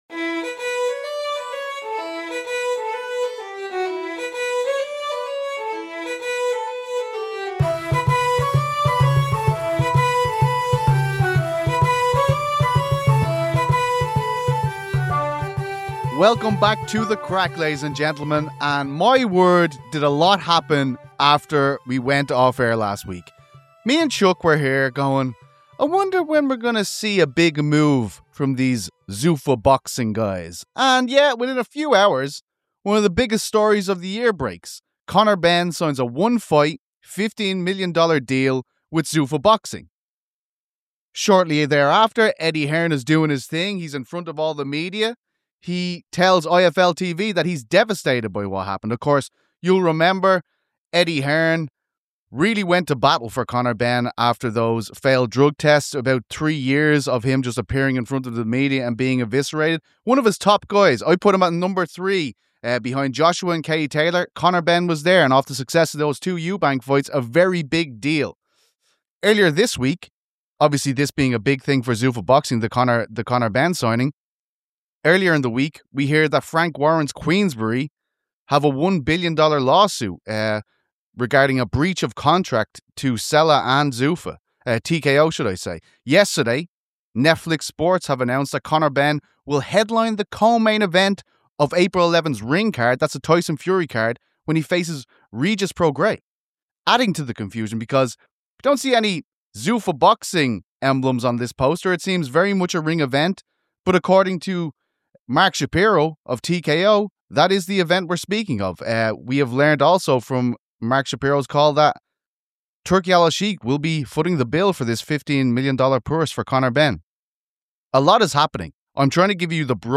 Every episode will feature interviews with the biggest names in combat sports, and analysis from Helwani and The Boys in the Back.